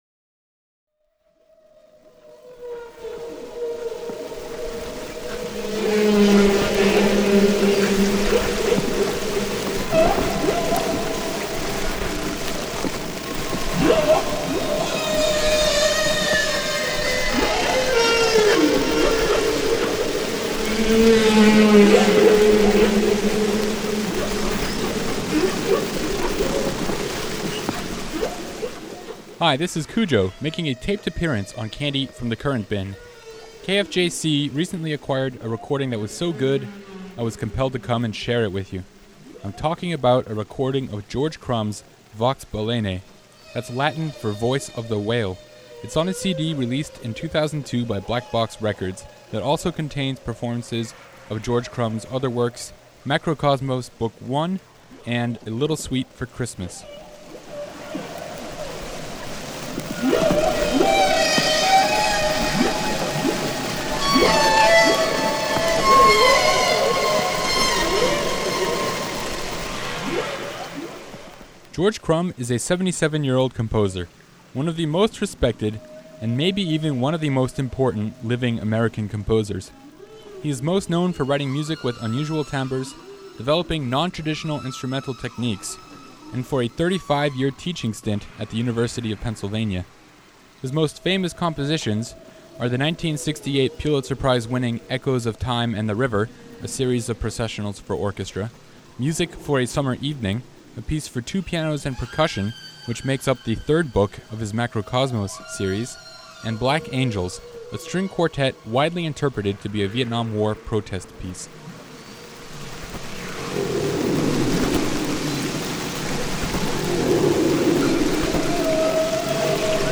The production still isn't great, but it's far better than the Ludwig Van piece I did earlier.